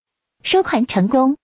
pay_success.wav